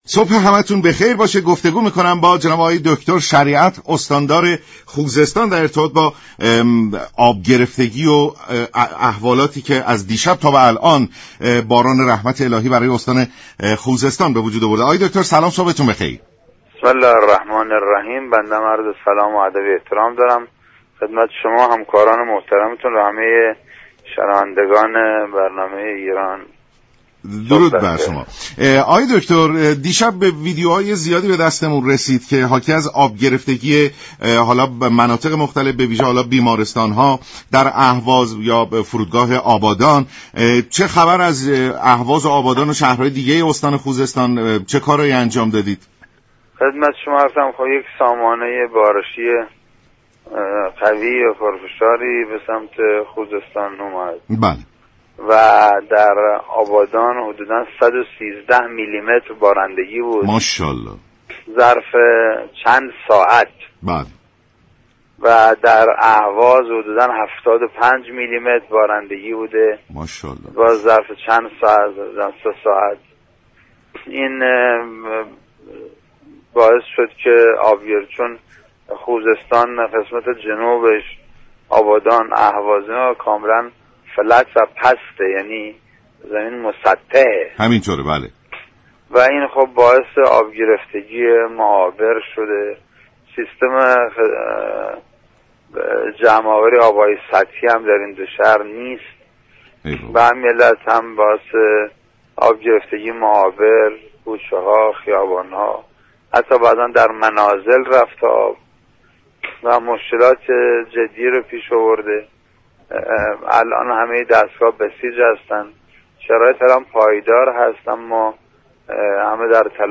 استاندار استان خوزستان در گفت و گو با رادیو ایران گفت: بارش ها در شهرهای آبادان و اهواز قطع شده و این سامانه هم اكنون به سمت بخش های دیگر استان چون ماهشهر، هندیجان و رامهرمز در حال حركت است.